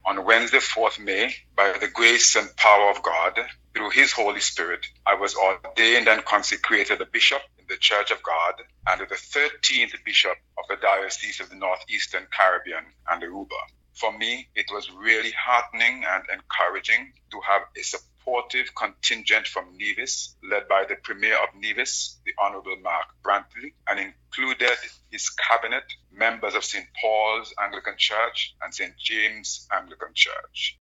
That was Dean Ernest Flemming speaking with the VONNEWSLINE about a recent ceremony in light of his elevation to the position of Bishop for the Diocese of the North Eastern Caribbean and Aruba (DNECA).